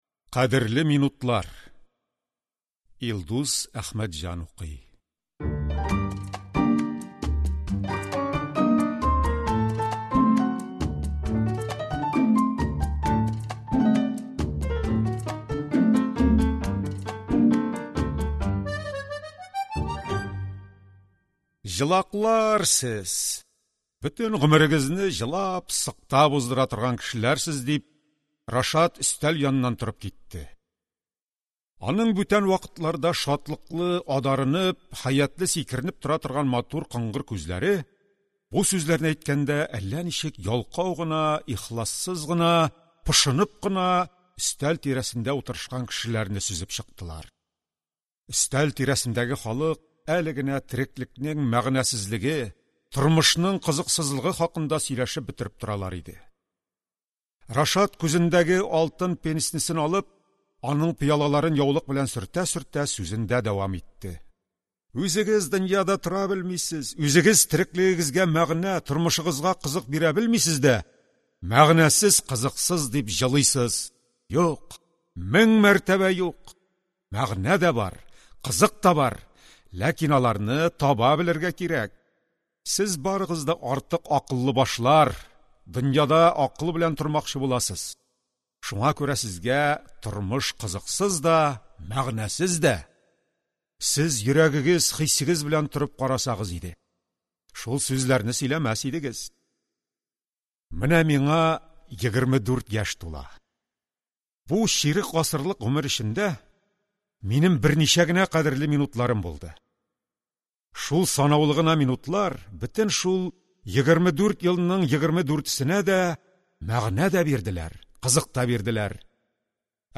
Аудиокнига Кадерле минутлар | Библиотека аудиокниг